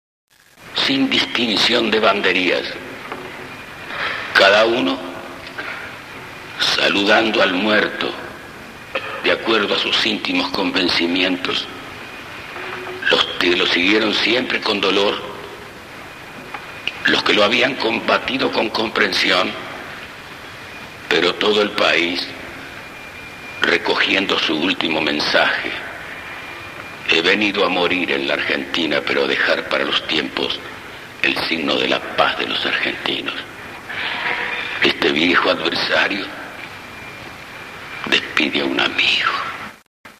Balbin_entierro_aPeron!s.mp3